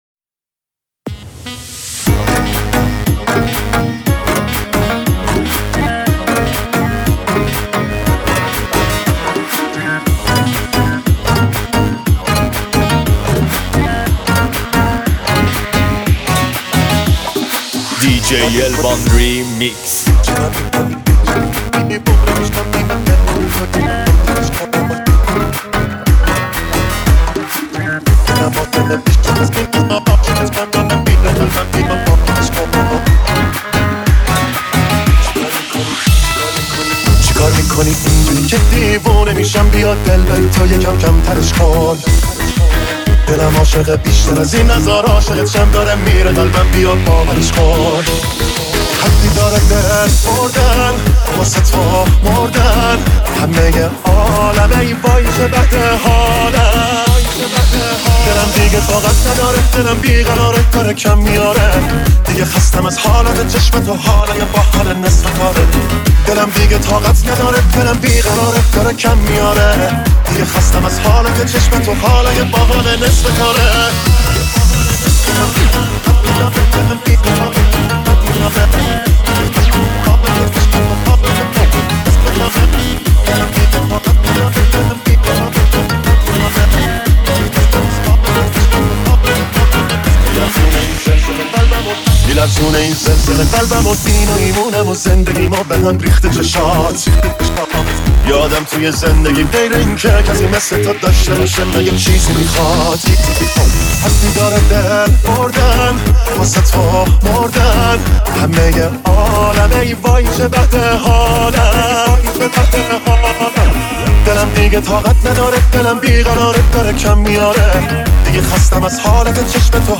ریمیکس شاد رقص و دنس
ریمیکس شاد مخصوص عروسی و رقص